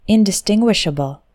Fast: